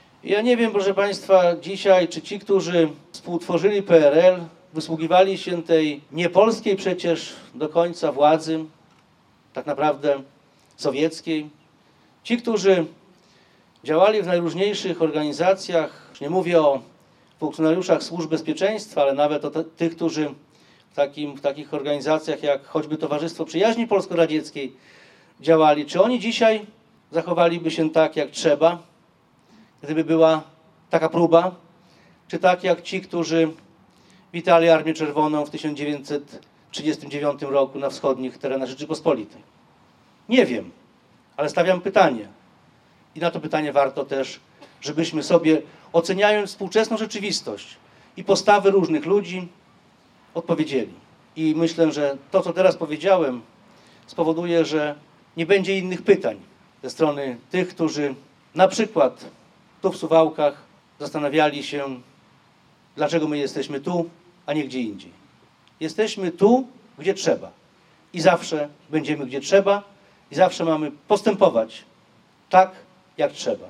Po mszy świętej w miejscowej konkatedrze i złożeniu kwiatów pod pomnikiem Marszałka Józefa Piłsudskiego głos pod Dąbkiem Wolności zabrał poseł Jarosław Zieliński, sekretarz stanu w Ministerstwie Spraw Wewnętrznych i Administracji.